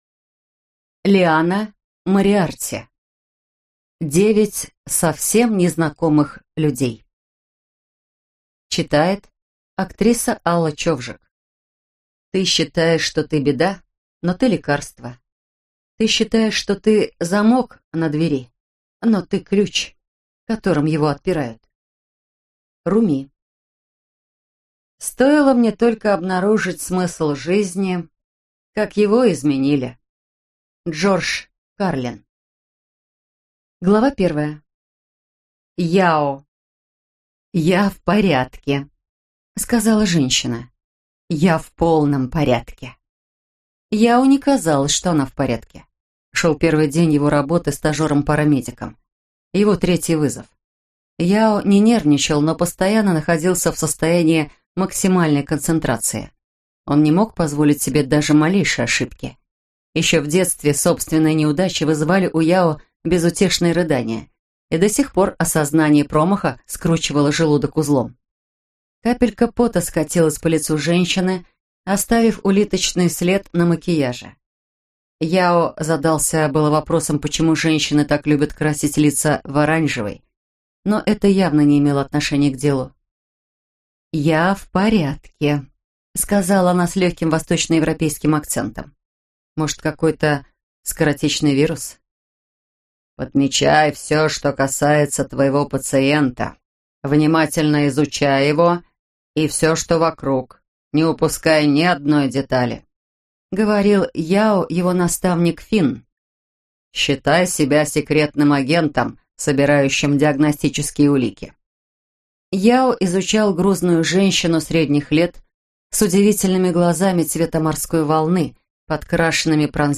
Аудиокнига Девять совсем незнакомых людей - купить, скачать и слушать онлайн | КнигоПоиск